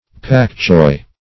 \pak-choi\